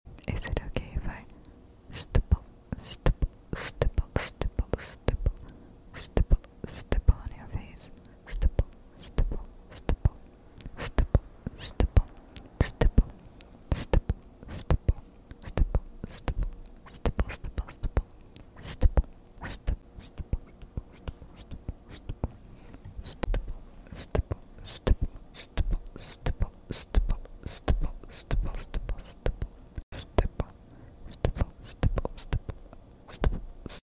Stippling Your Face ASMR 🖌🖌 Sound Effects Free Download